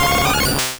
Cri de Mimitoss dans Pokémon Rouge et Bleu.